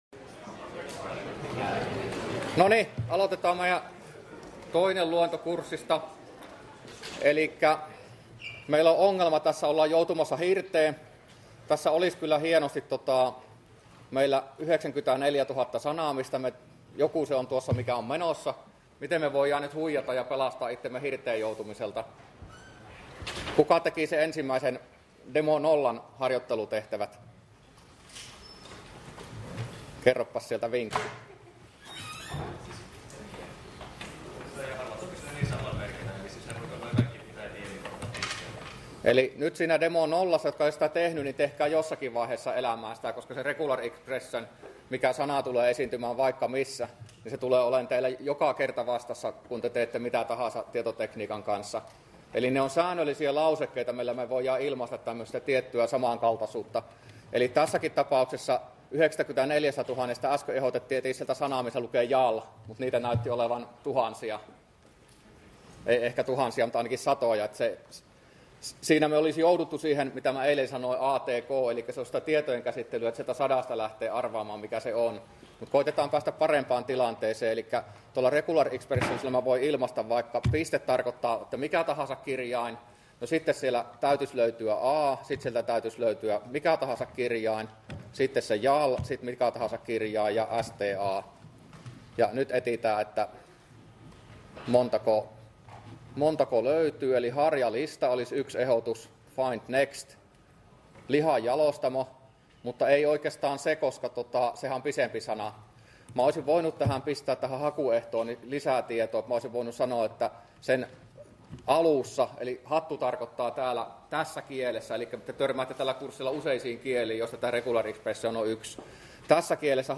luento02